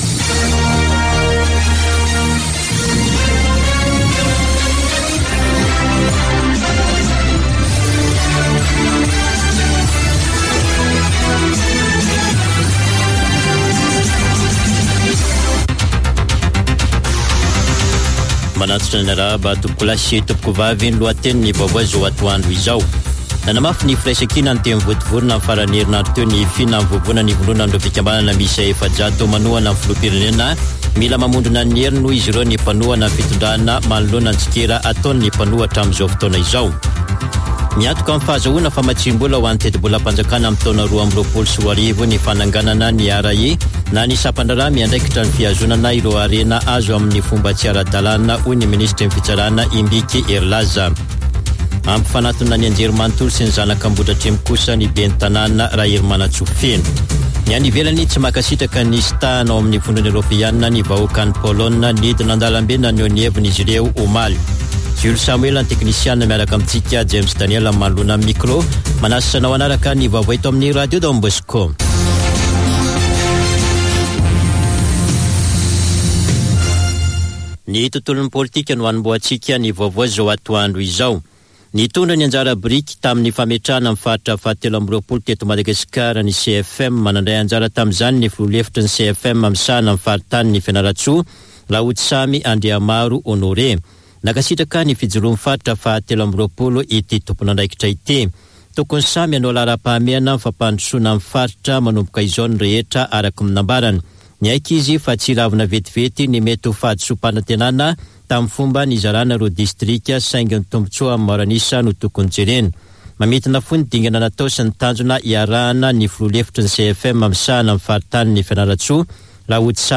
Radio Don Bosco - [Vaovao antoandro] Alatsinainy 11 oktobra 2021